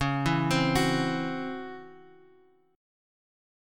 Listen to C#mM11 strummed